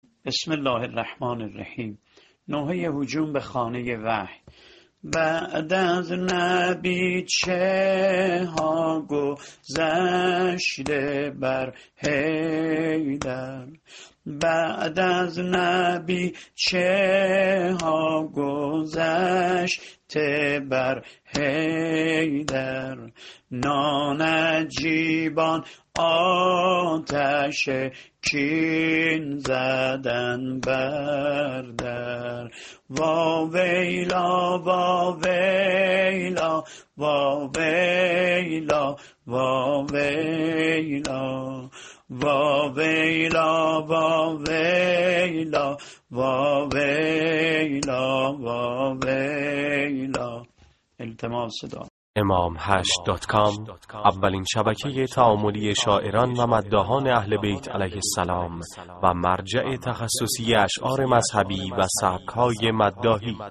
نوحه سینه زنی فاطمیه